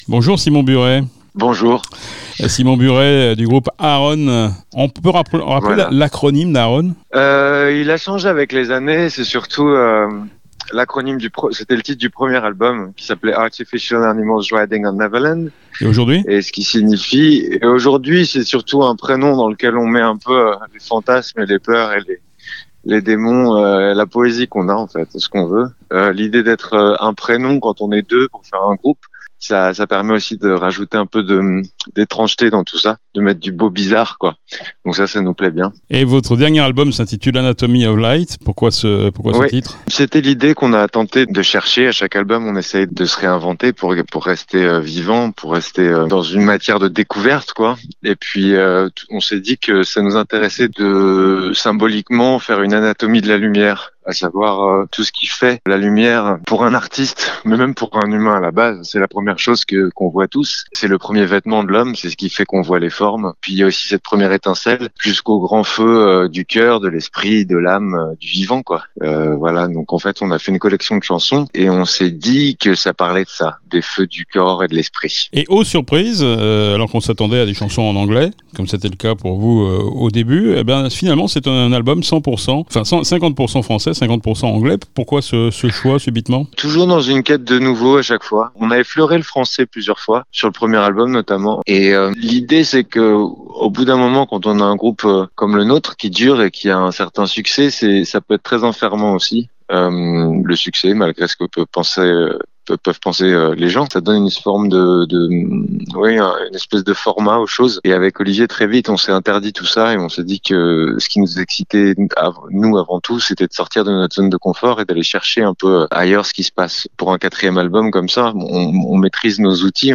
SIMON-BURET-AARON-avec-extraits.mp3